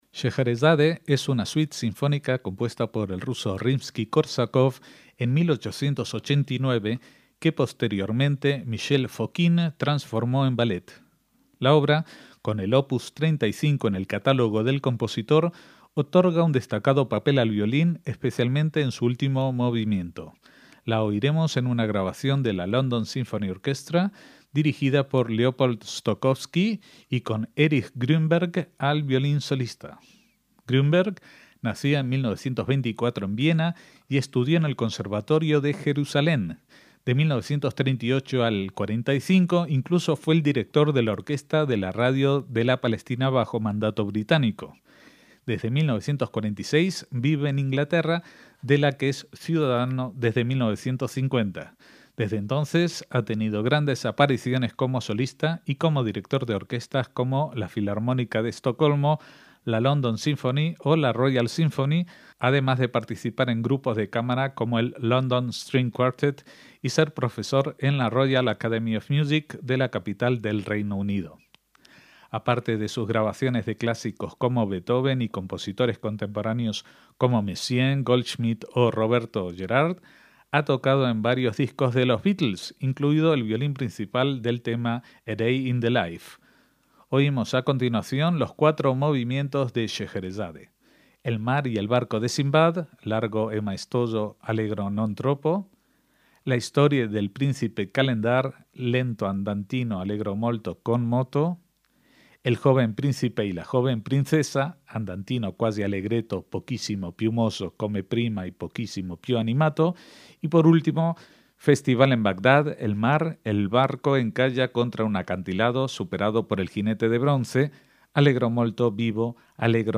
MÚSICA CLÁSICA
clásico sinfónico
poema sinfónico
La temática oriental y exótica permitió al compositor lucirse con la orquestación
el destacado papel solista del violín